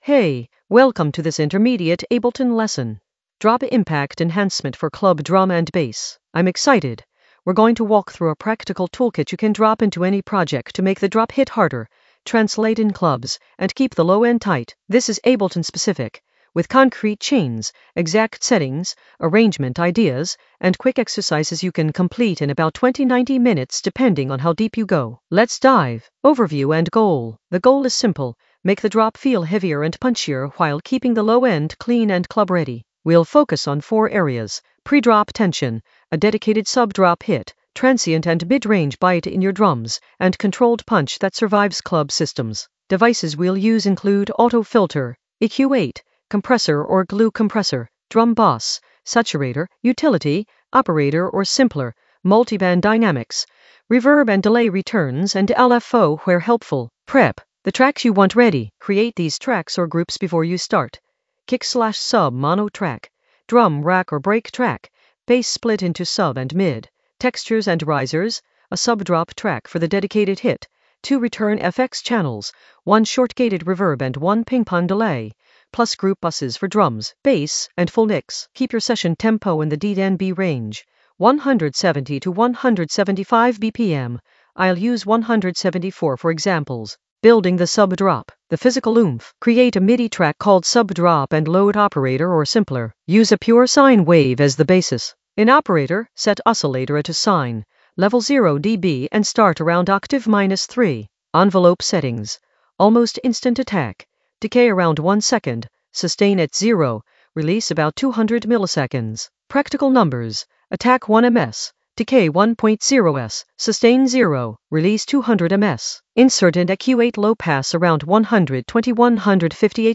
Narrated lesson audio
The voice track includes the tutorial plus extra teacher commentary.
Teacher tone: energetic, clear, and professional ✅